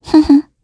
Gremory-Vox_Happy1_kr.wav